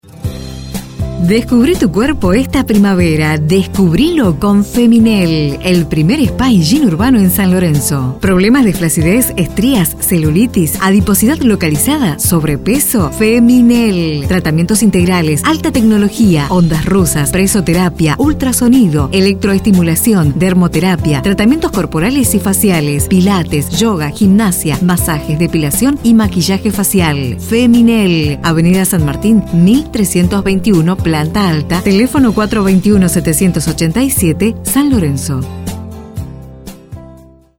Junge dynamische Sprecherin mit Erfahrung in Werbung für Radio und TV
kastilisch
Sprechprobe: Industrie (Muttersprache):
female voice over artist spanish.